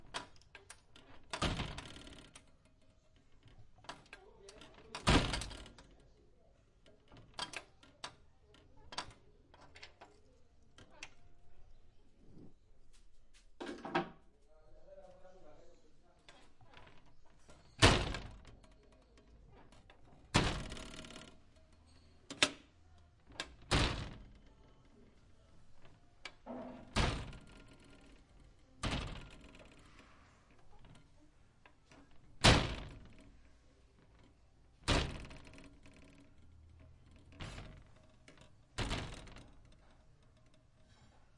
乌干达 " 金属厚重的地牢监狱门解锁打开关闭砰砰作响，舱门打开关闭滑行吱吱作响各种角度的声音
描述：金属重型地牢重金属监狱门解锁打开关闭嘎嘎声和舱口盖打开关闭幻灯片吱吱声各种外部视角上offmic + bg宿舍厨房sound2.wav